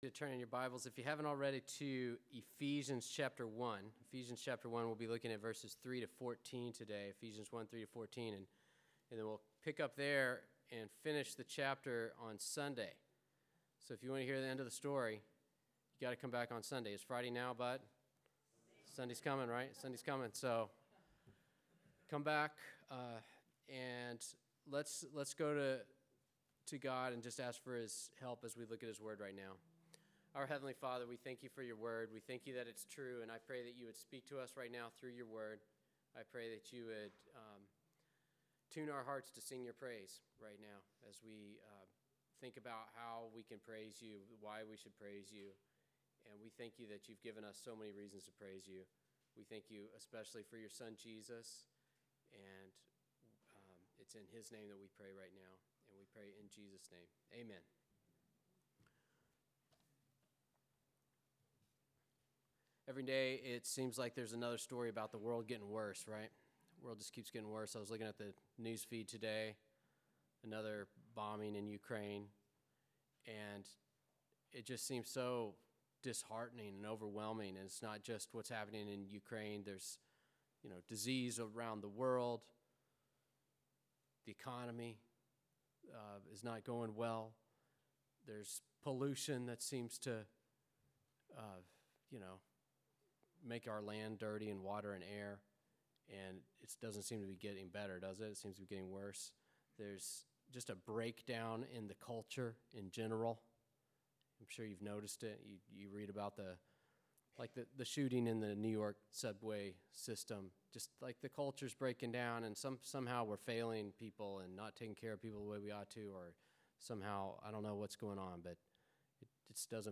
Listen to sermons by our pastor on various topics.
Good Friday